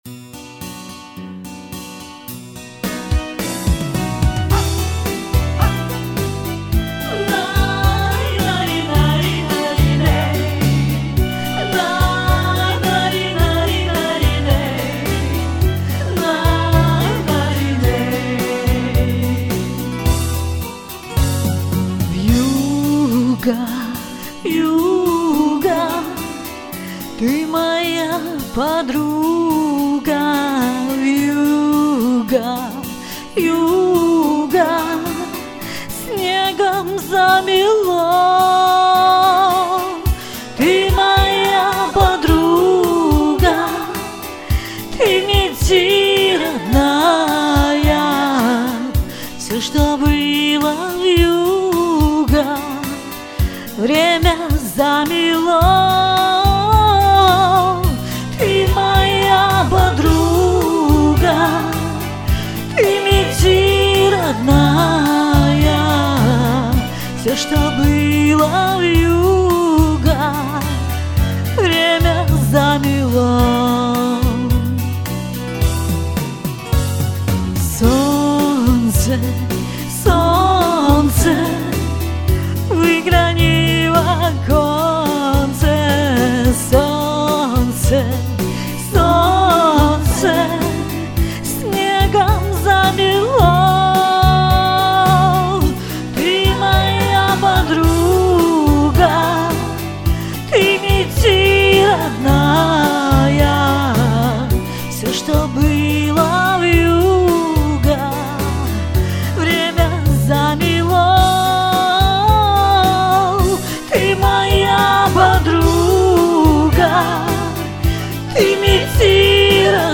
(цыганская)